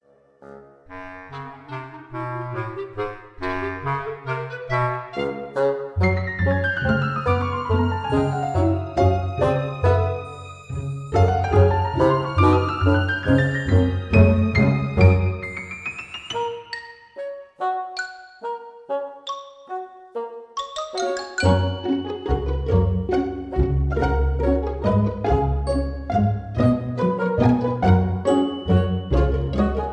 Aus dem Kindertanztheater